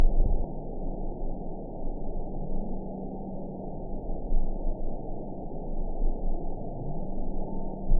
event 915302 date 11/27/22 time 05:12:19 GMT (2 years, 6 months ago) score 5.28 location TSS-AB09 detected by nrw target species NRW annotations +NRW Spectrogram: Frequency (kHz) vs. Time (s) audio not available .wav